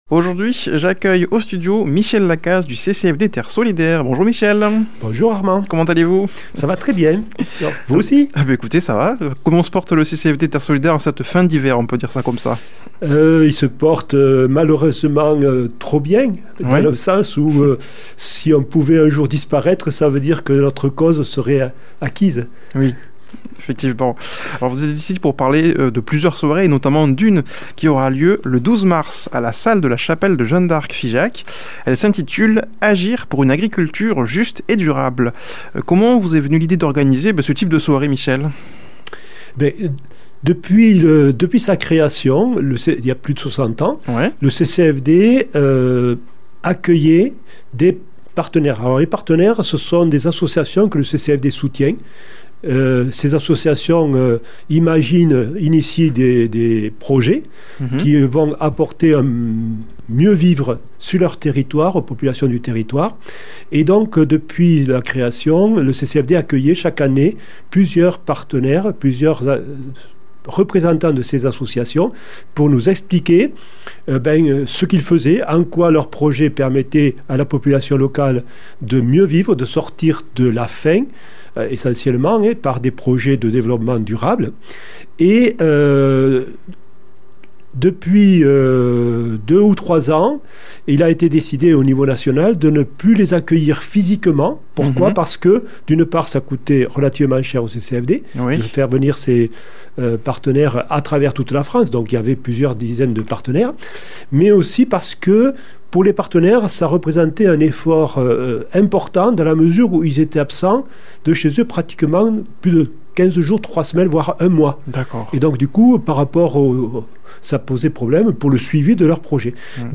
a comme invité au studio